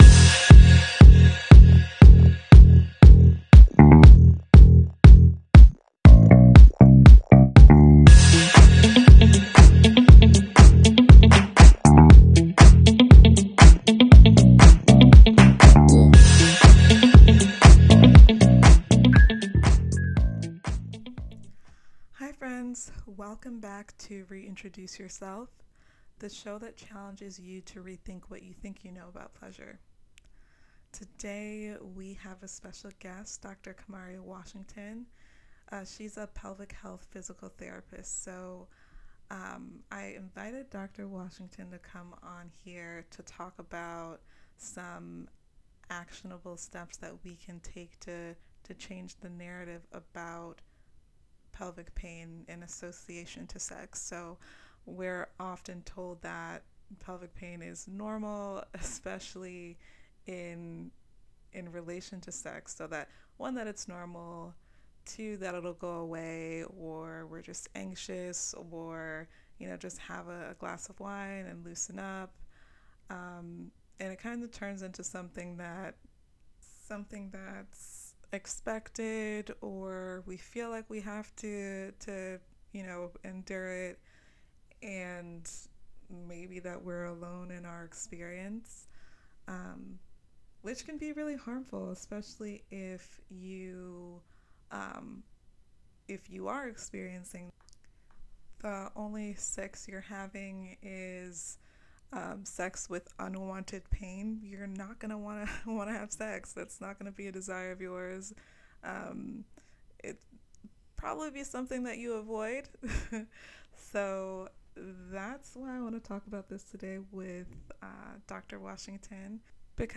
Reintroduce Yourself is an interview style podcast focused on sexuality and pleasure.